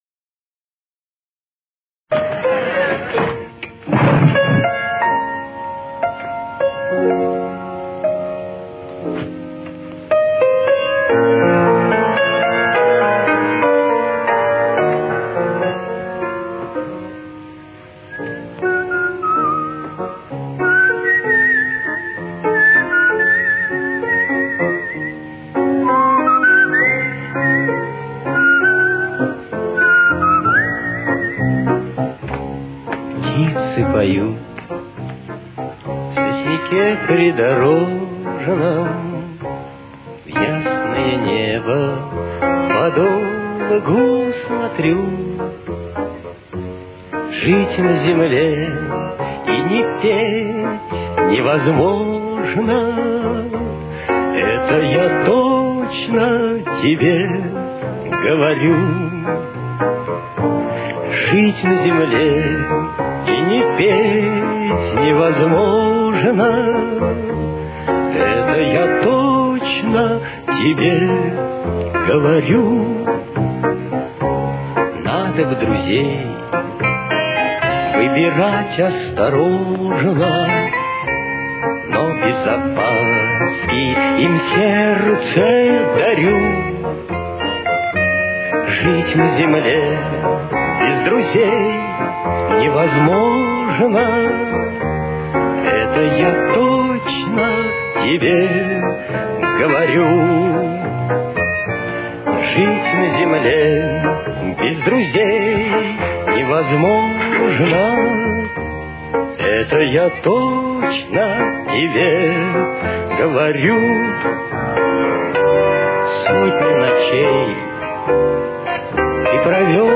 Темп: 71.